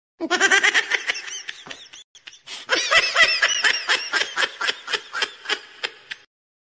Гуфи ах смех ха ха ха